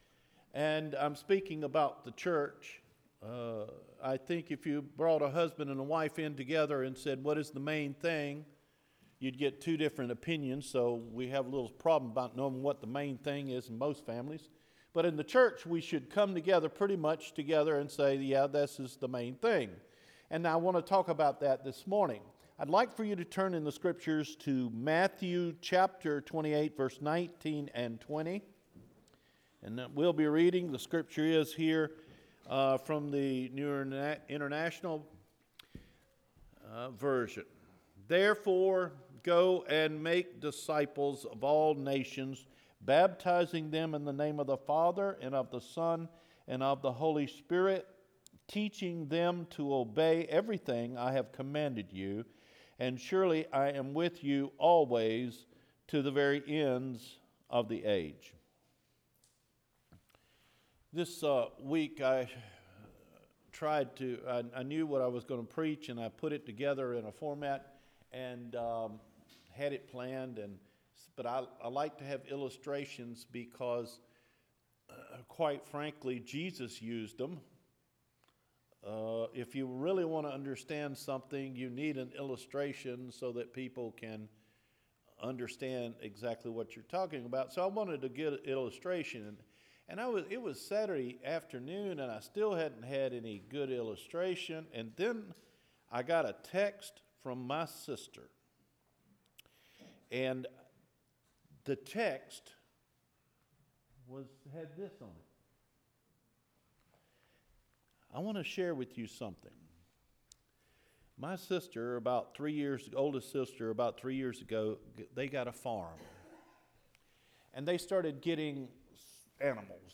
Discipleship; The Great Commission – January 28 Recorded Sermon